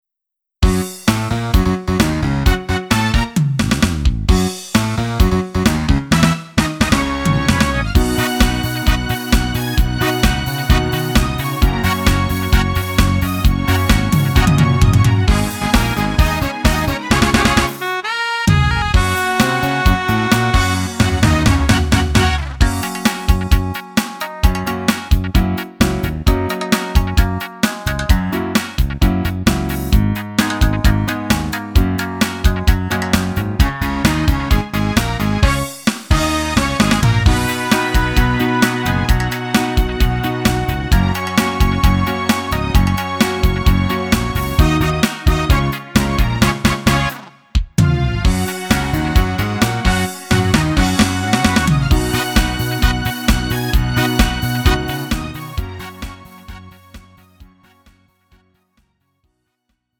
음정 원키 3:21
장르 가요 구분 Lite MR
Lite MR은 저렴한 가격에 간단한 연습이나 취미용으로 활용할 수 있는 가벼운 반주입니다.